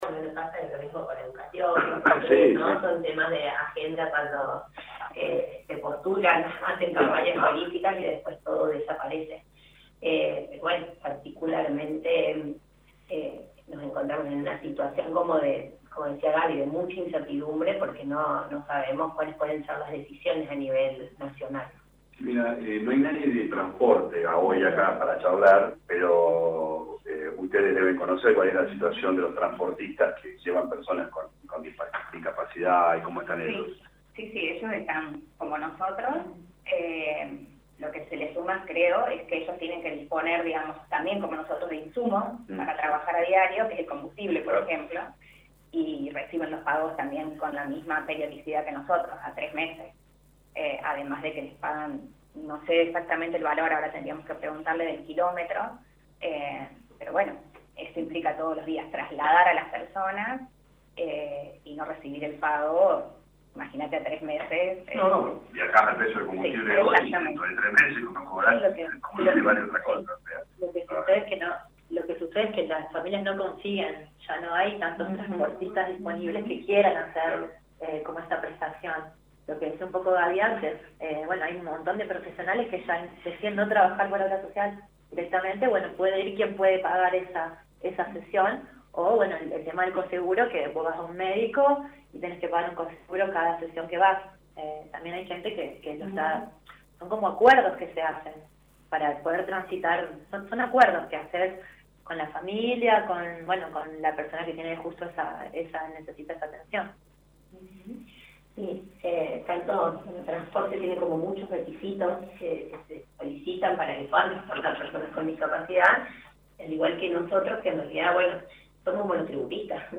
En diálogo con LA RADIO 102.9 FM intengrantes del Centro Interdisciplinario Crecer informaron cómo afecta a los profesionales de salud la emergencia en discapacidad.